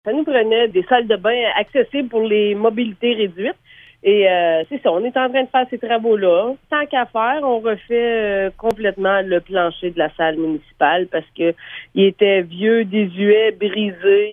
Voici les propos de la mairesse, Anne Potvin :